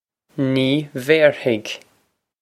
Nee vare-hig
This is an approximate phonetic pronunciation of the phrase.